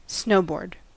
Ääntäminen
IPA : /snəʊbɔː(ɹ)d/